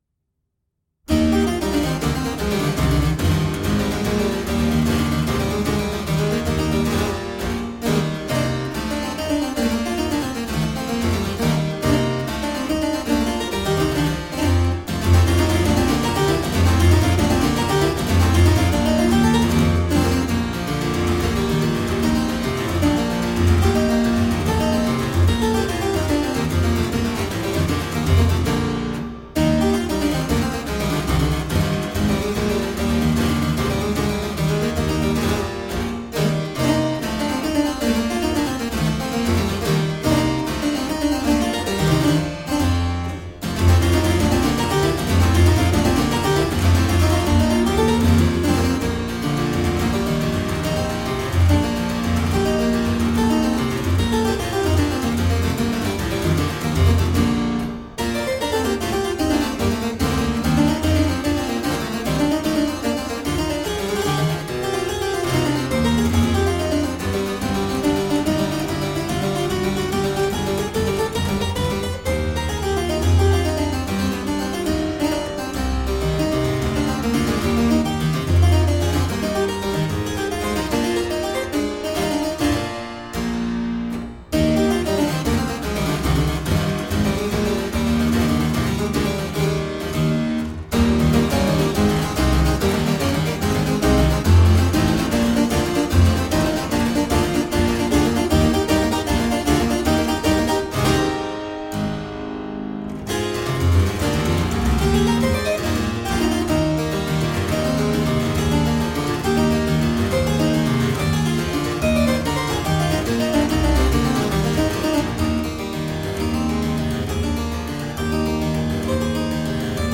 Vibrant harpsichord-music.
Classical, Baroque, Instrumental
Harpsichord